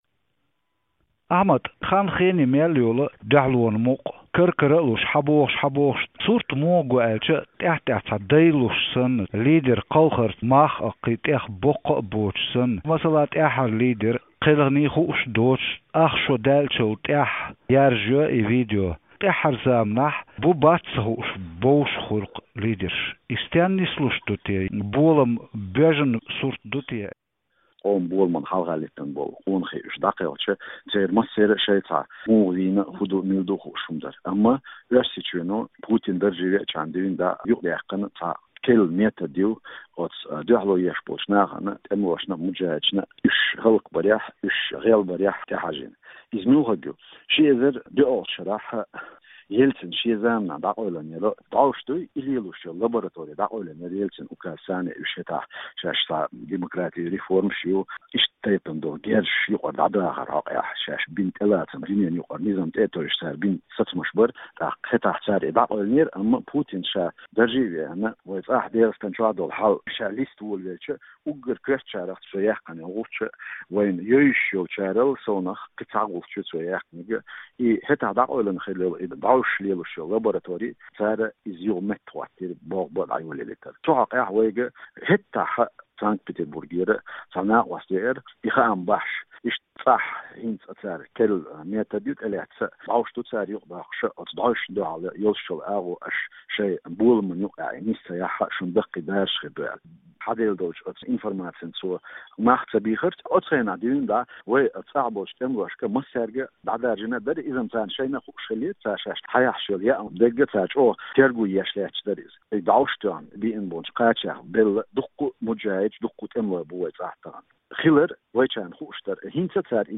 Оцу хьокъехь къамел до Маршо Радионо Ичкерин куьйгалхочуьнца Закаев Ахьмадца.